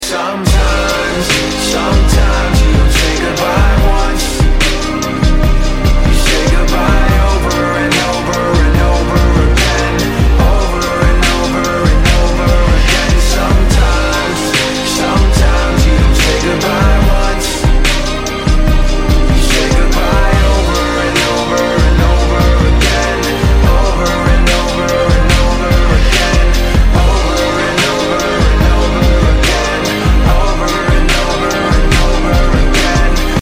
грустные
Rap-rock
Alternative Rap